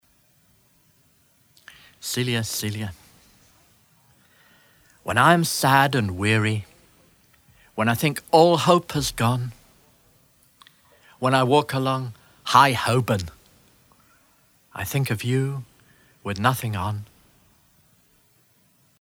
Recordings from a selection of 72 Poems on the Underground originally recorded on tape and published as a Cassell Audiobook in 1994
Celia Celia by Adrian Mitchell read by Adrian Mitchell